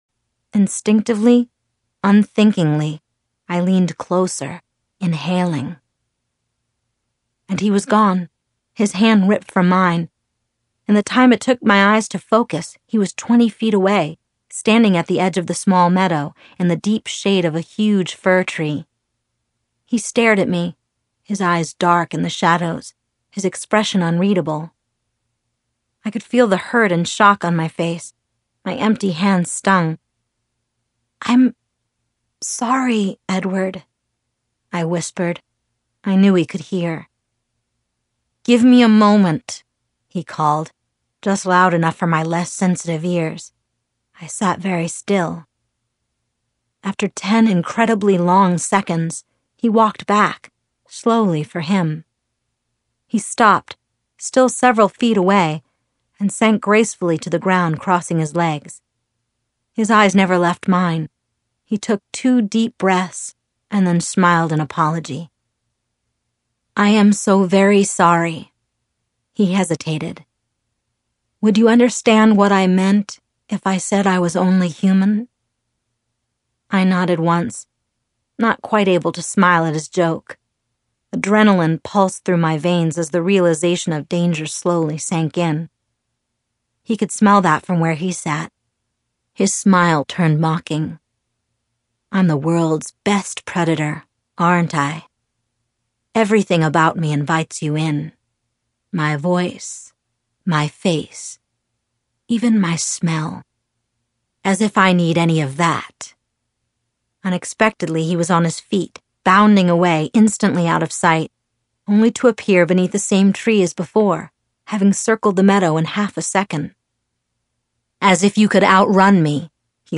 Audiobook - Voice-Over Performer